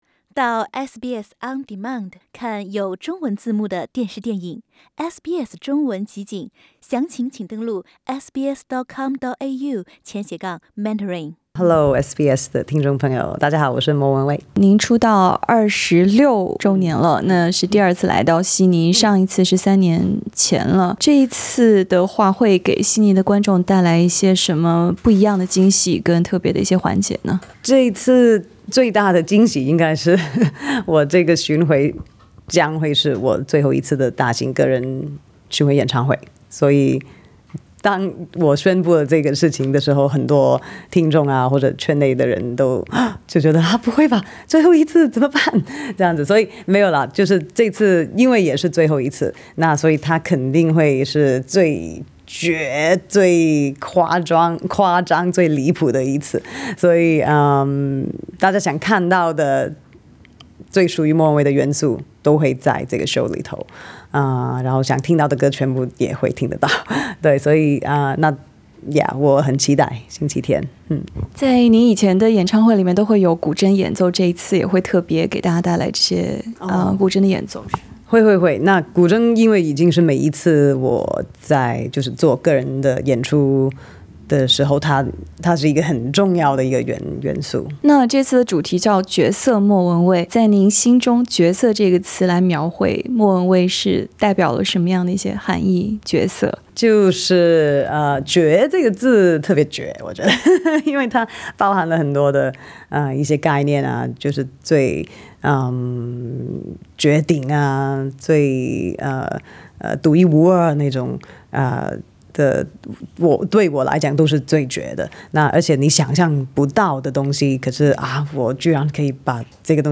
【專訪莫文蔚】：巡演今年“絕色收官”，下一站"中國百老彙"